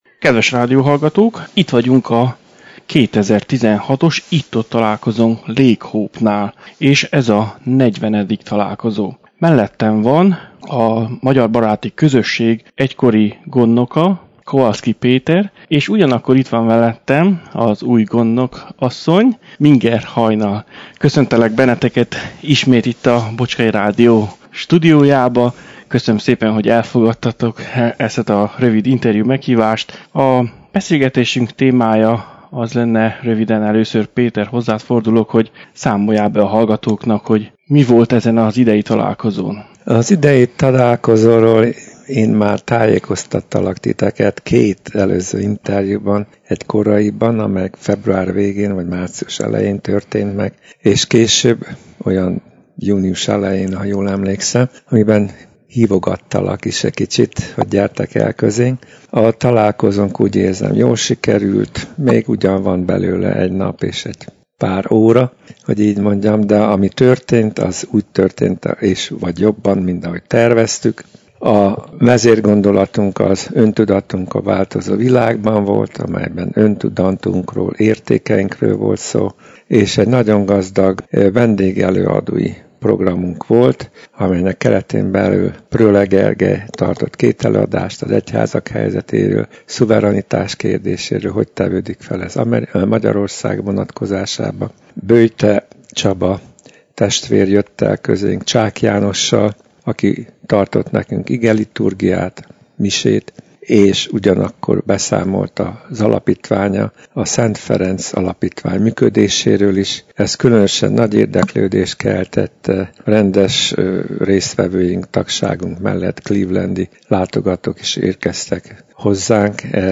A találkozónak négy vendég előadója volt, akikkel készítettem interjúkat, amelyeket a következő heti adásainkban fogunk meghallgatni.